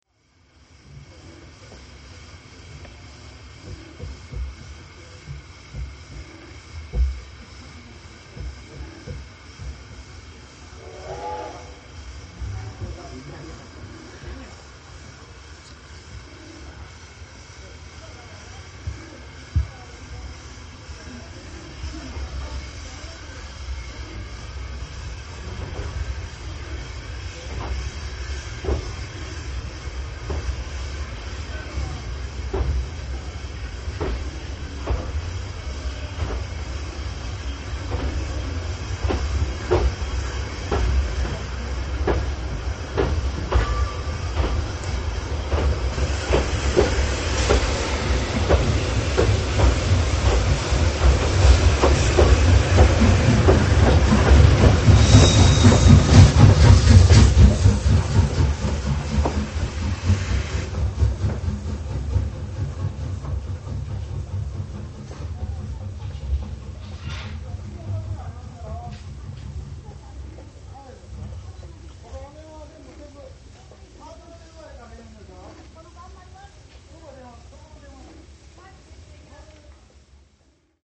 鉄道サウンド・蒸気機関車の音
（音質少々難あり。）
北宇智駅をバックで出て行く和歌山市行き５２７レ　Ｃ５８ 　　　　　　　　　　　　　　　　　　　ＭＰ-３モノラル　６３１ＫＢ　１分２０秒
対向の５２６レの窓から録音。機関車が横を通る時、ボイラの熱気と圧力をもろに感じました。